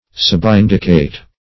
Search Result for " subindicate" : The Collaborative International Dictionary of English v.0.48: Subindicate \Sub*in"di*cate\, v. t. [Pref. sub + indicate: cf. L. subindicare.]